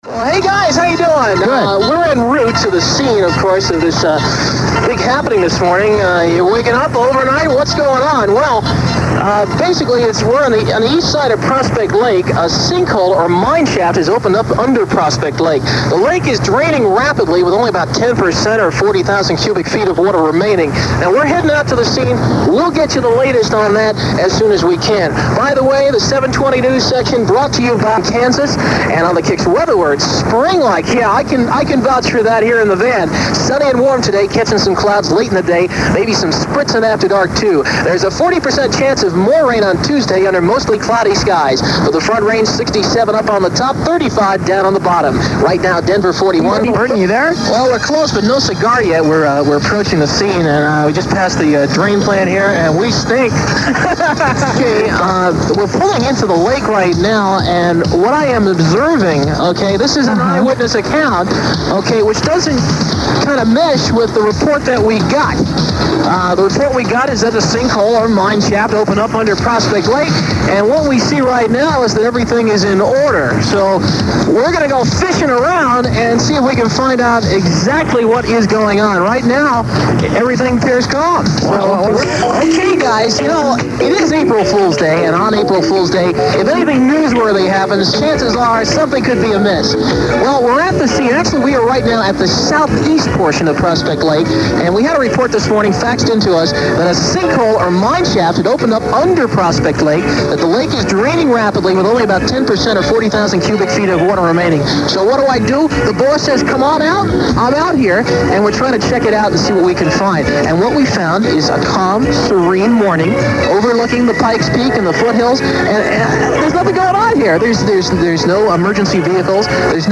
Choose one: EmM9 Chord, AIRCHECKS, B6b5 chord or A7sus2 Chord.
AIRCHECKS